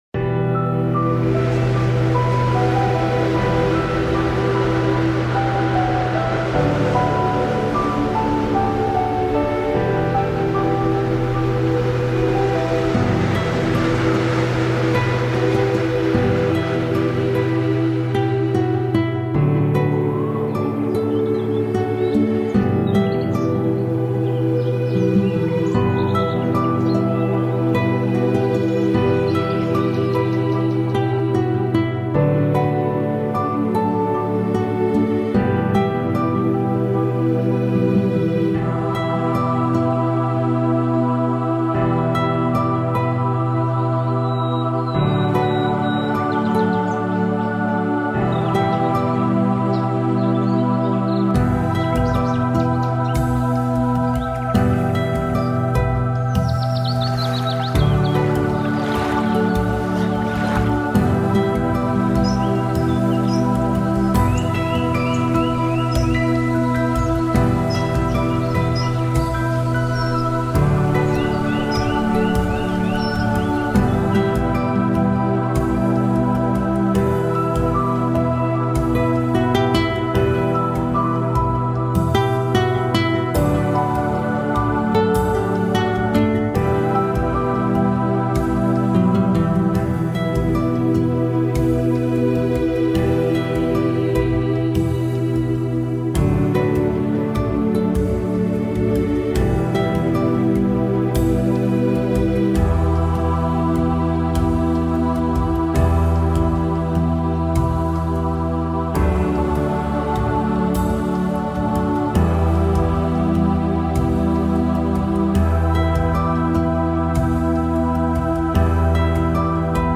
Winterlandschaft-Schnee-Luftklarheit: Schnee + Klarheit entspannender Luft